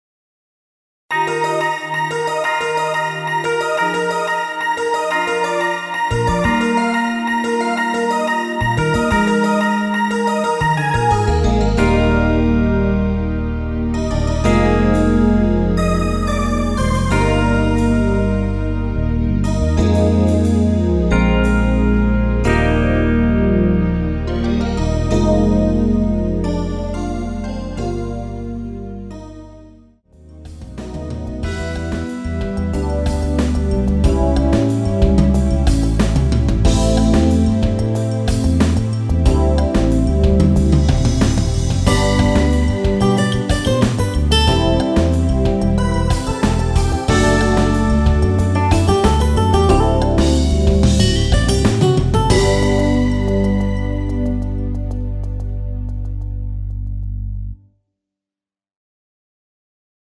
엔딩이 페이드아웃이라 노래하기 편하게 엔딩부분을 다시 만들었습니다
원키에서(-2)내린 MR입니다.
Eb
앞부분30초, 뒷부분30초씩 편집해서 올려 드리고 있습니다.